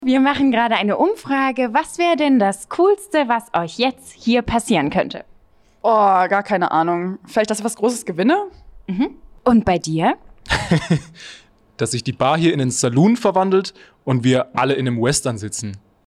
Dieses Mikrofon ist etwas „schwerhörig“, d.h. es nimmt nur den Schall aus unmittelbarer Nähe auf.
Deshalb eignet es sich sehr gut für wechselnde Sprecher*innen in einer lauten Umgebung.
Reportermikro
Reportermikro.mp3